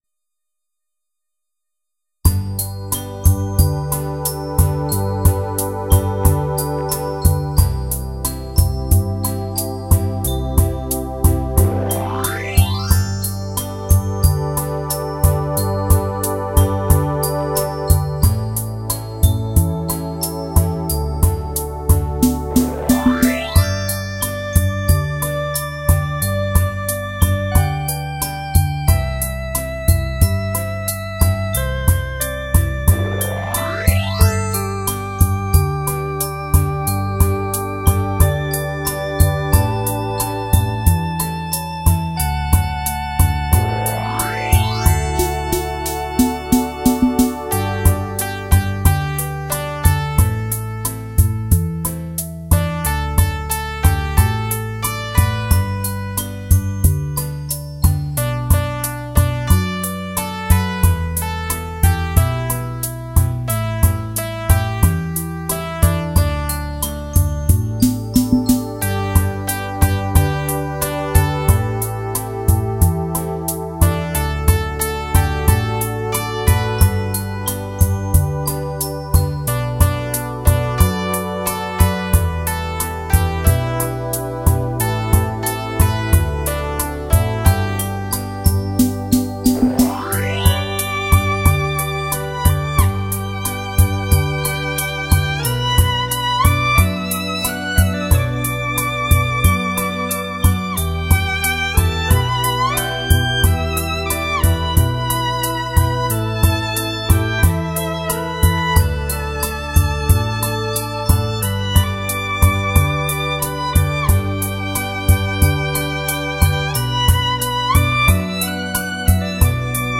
电子琴与小提琴-两琴相乐”《又见茉莉花